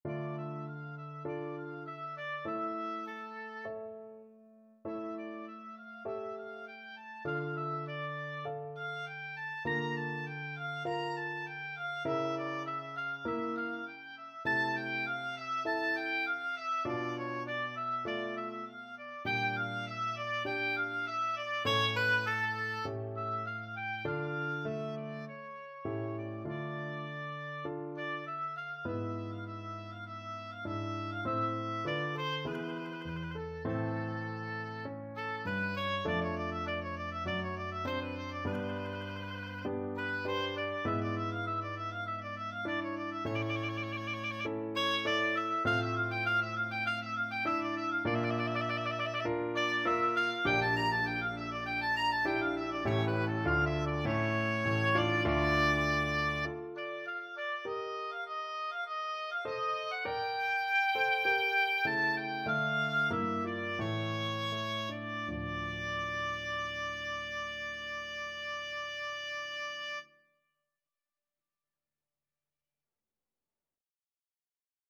D minor (Sounding Pitch) (View more D minor Music for Oboe )
4/4 (View more 4/4 Music)
Largo
A5-Bb6
Classical (View more Classical Oboe Music)